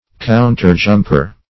Meaning of counterjumper. counterjumper synonyms, pronunciation, spelling and more from Free Dictionary.
Search Result for " counterjumper" : The Collaborative International Dictionary of English v.0.48: Counterjumper \Coun"ter*jump`er\ (koun"t[~e]r-j?mp`?r), n. A salesman in a shop; a shopman; -- used contemptuously.